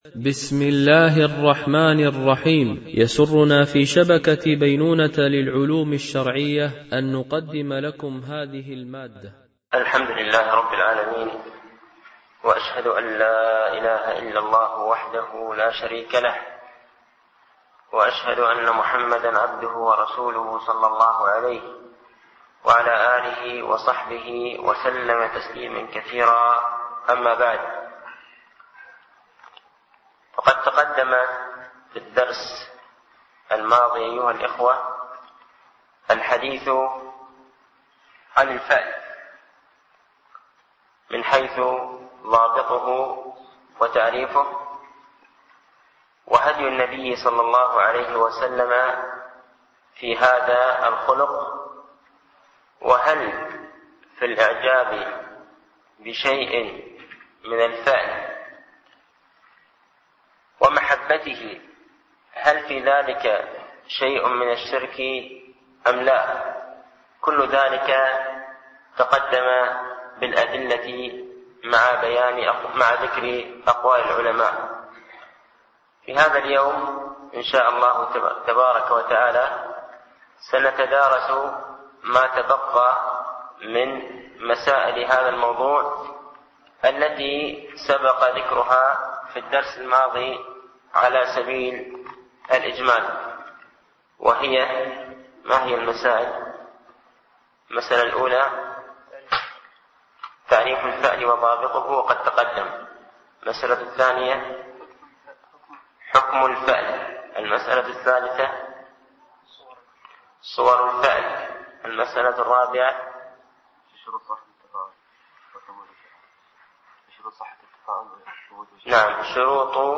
الدرس
Mono